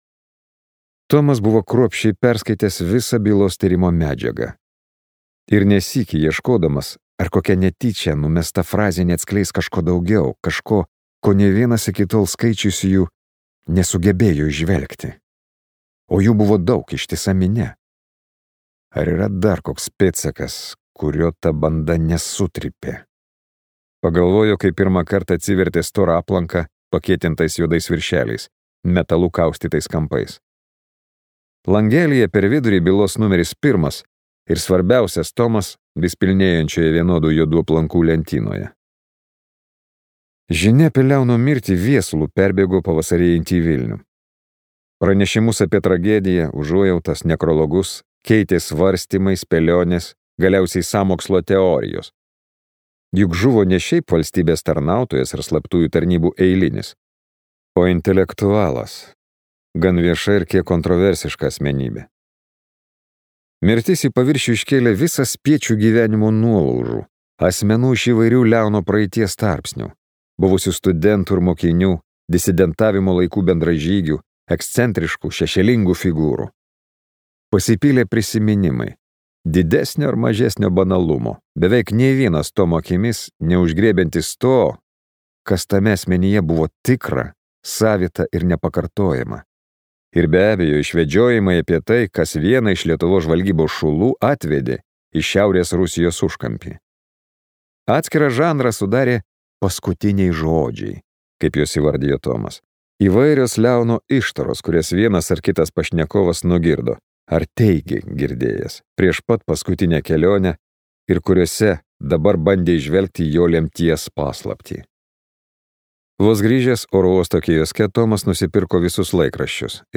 Manto Adomėno audioknyga, tai metų geriausios knygos titulą laimėjęs, įtraukiantis šnipų romanas „Moneta & Labirintas“